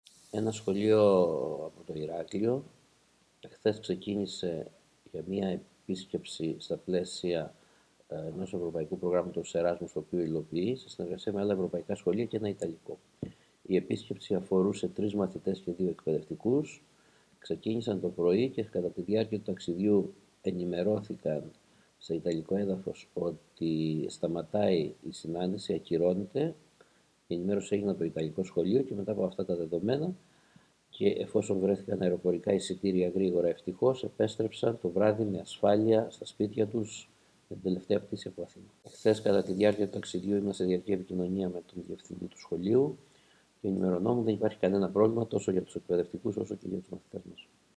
Ακούστε εδώ τις δηλώσεις του Περιφερειακού Διευθυντή Εκπαίδευσης Κρήτης Μ. Καρτσωνάκη: